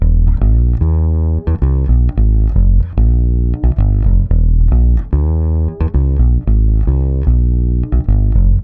-AL AFRO F.wav